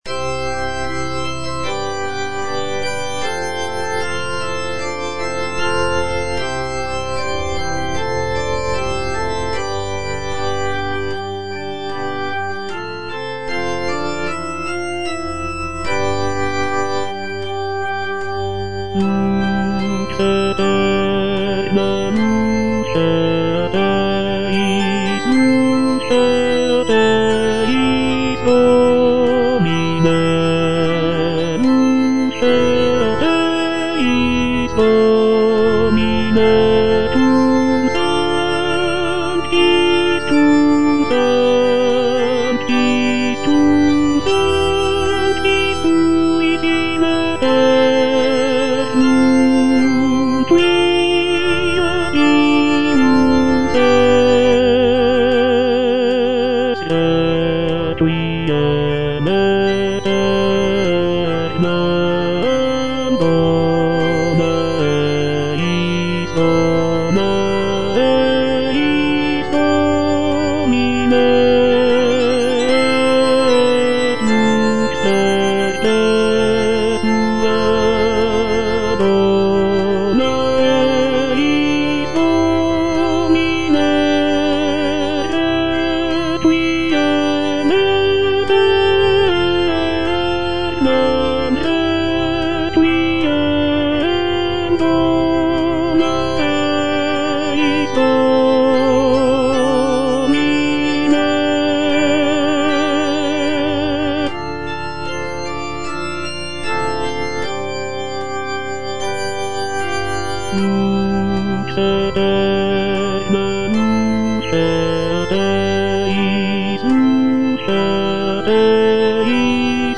Tenor (Voice with metronome
is a sacred choral work rooted in his Christian faith.